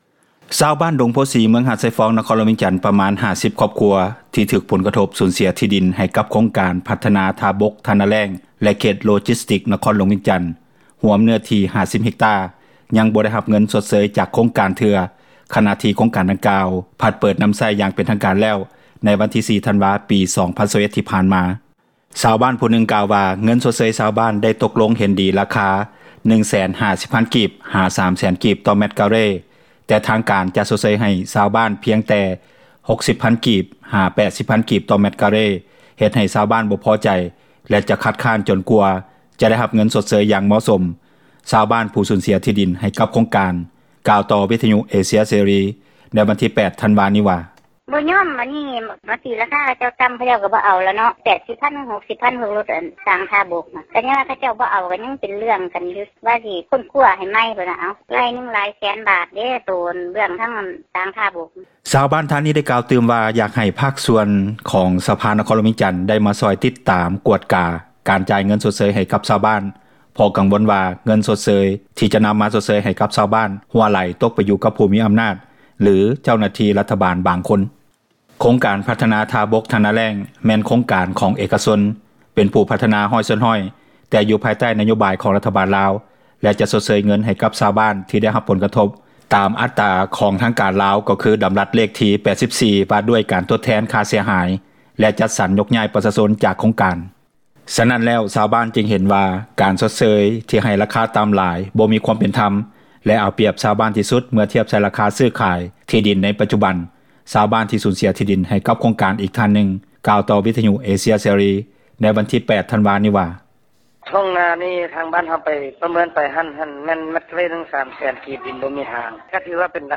ສະນັ້ນແລ້ວຊາວບ້ານຈຶ່ງເຫັນວ່າ ເປັນການຊົດເຊີຍ ທີ່ໃຫ້ລາຄາຕໍ່າຫຼາຍ ບໍ່ມີຄວາມເປັນທັມ ແລະເອົາປຽບຊາວບ້ານທີ່ສຸດ ເມື່ອທຽບໃສ່ລາຄາ ຊື້-ຂາຍ ທີ່ດິນໃນປັຈຈຸບັນ. ຊາວບ້ານ ທີ່ສູນເສັຽທີ່ດິນ ໃຫ້ກັບໂຄງການ ອີກທ່ານນຶ່ງກ່າວຕໍ່ ວິທຍຸເອເຊັຽເສຣີ ໃນວັນທີ 8 ທັນວາ ນີ້ວ່າ:
ສ່ວນຊາວບ້ານ ເຂດເມືອງຫາດຊາຍຟອງ, ຫາກຊາວບ້ານຍັງບໍ່ໄດ້ເງິນຊົດເຊີຍ ທາງສະພານະຄອນຫຼວງວຽງຈັນ ກໍຈະຕິດຕາມກວດກາກັບ ຫ້ອງການປົກຄອງເມືອງຫາດຊາຍຟອງ ແລະບໍຣິສັດຜູ້ພັທນາ ໂຄງການເພີ່ມຕື່ມວ່າ ເພິ່ນດຳເນີນການ ໄປຮອດຂັ້ນຕອນໃດແລ້ວ. ເຈົ້າໜ້າທີ່ ສະພານະຄອນຫຼວງວຽງຈັນ ທ່ານນຶ່ງກ່າວຕໍ່ ວິທຍຸເອເຊັຽເສຣີ ໃນມື້ວັນທີ 8 ທັນວານີ້ວ່າ: